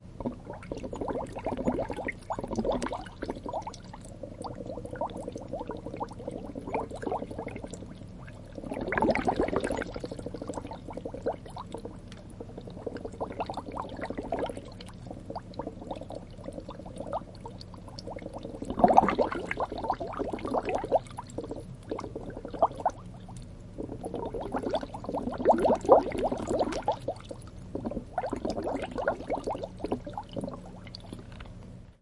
水的沸腾
描述：在飞利浦开水器（1500瓦）中记录水从0摄氏度到100摄氏度的沸腾情况。使用奥林巴斯VN480记录仪进行记录。事后没有处理。